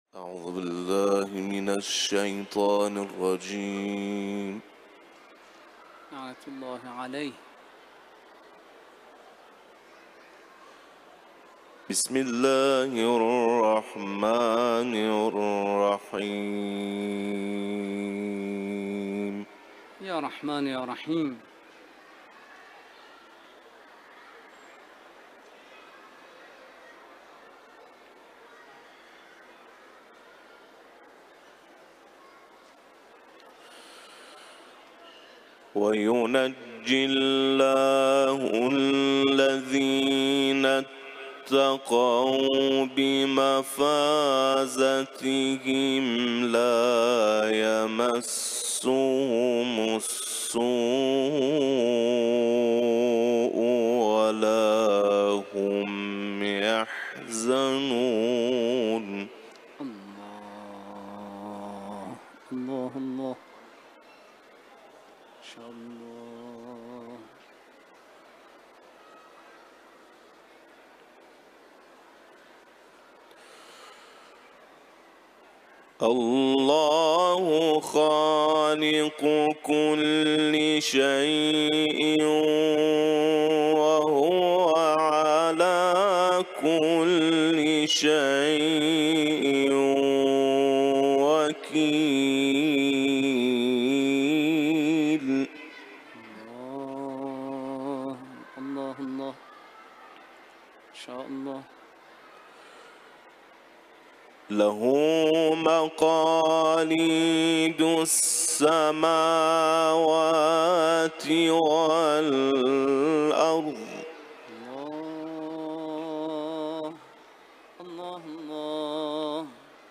Bacaan Al-Quran
dalam majlis di Haram Mutahhar Razavi